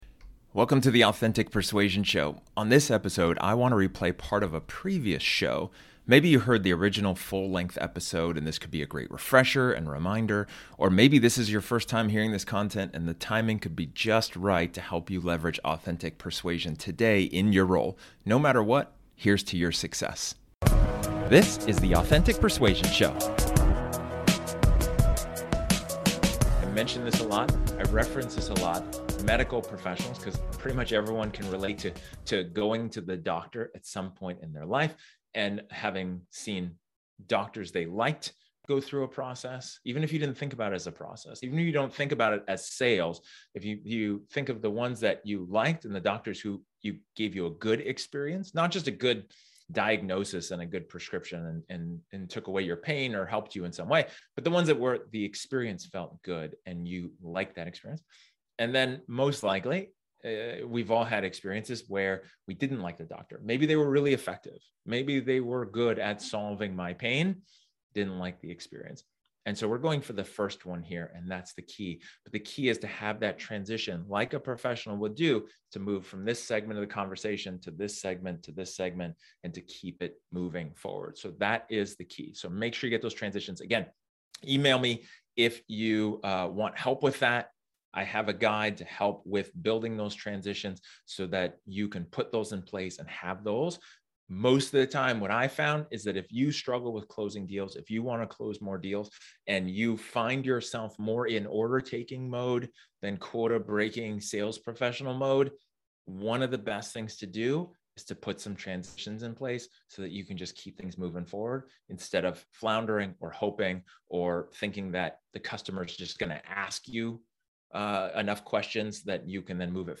This episode is an excerpt from one of my training sessions where I talk about building rapport.